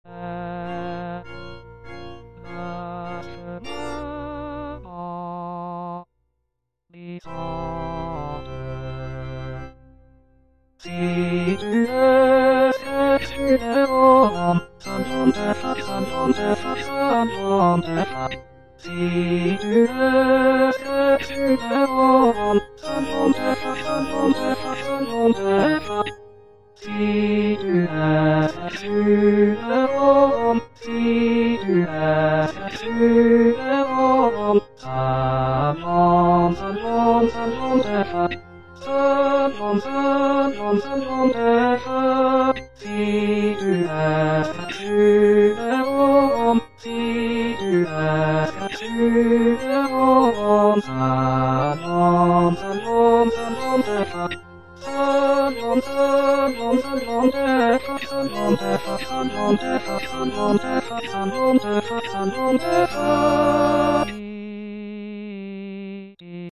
Parole 5: Sitio        Prononciation gallicane (à la française)